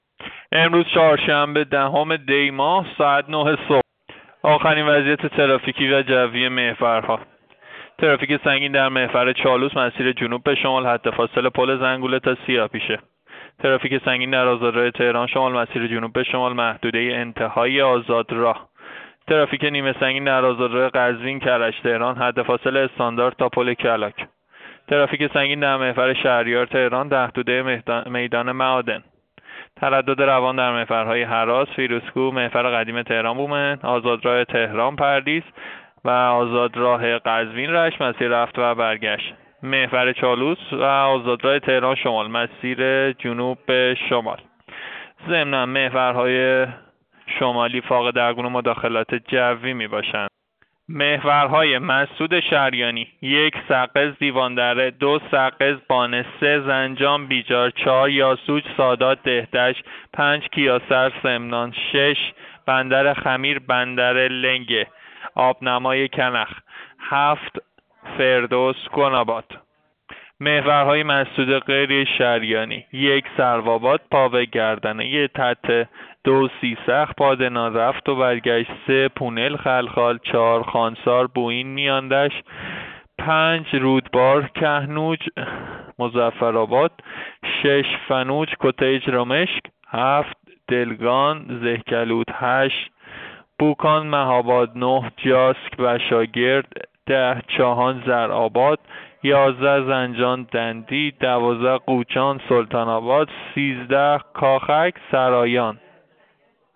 گزارش رادیو اینترنتی از آخرین وضعیت ترافیکی جاده‌ها ساعت ۹ دهم دی؛